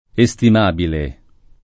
estimabile [ e S tim # bile ]